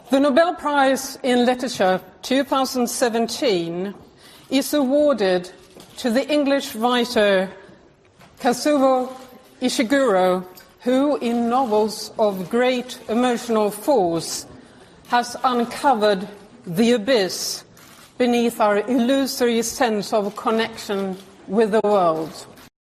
Así lo ha dado a conocer la secretaria permanente de la Academia sueca, Sara Danius, en Estocolmo